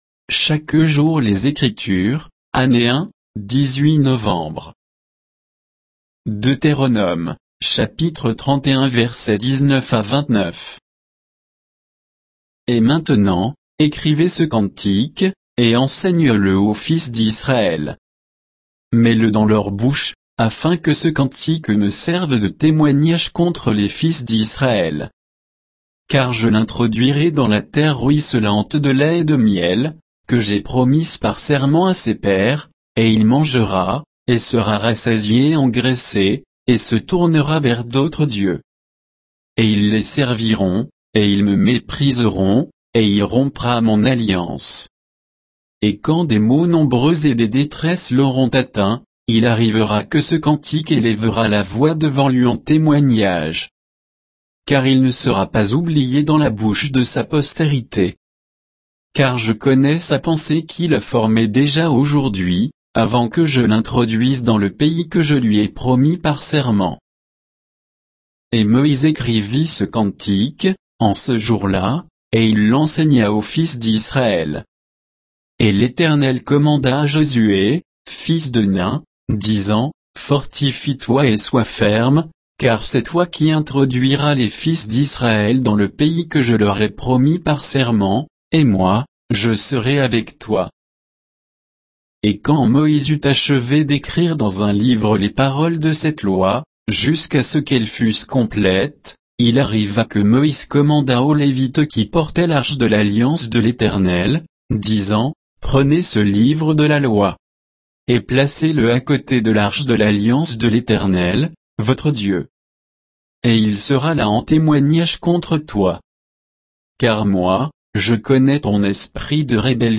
Méditation quoditienne de Chaque jour les Écritures sur Deutéronome 31, 19 à 29